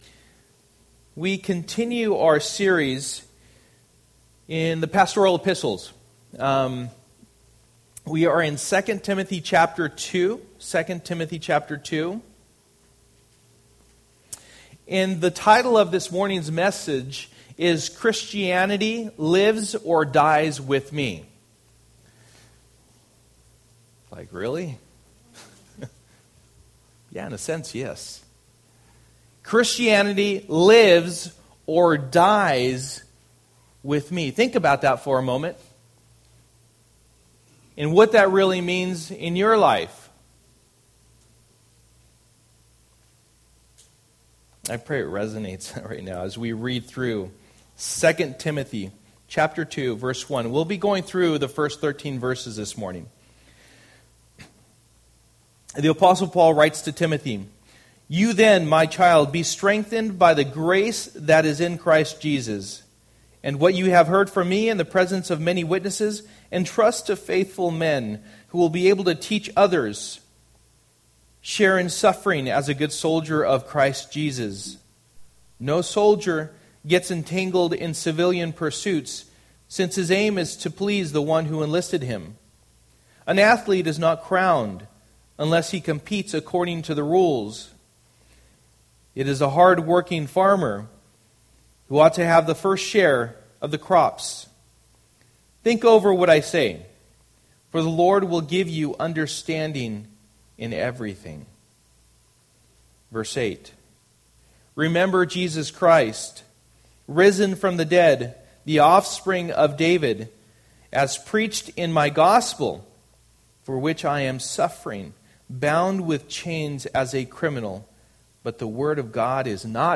Passage: 2 Timothy 2:1-13 Service: Sunday Morning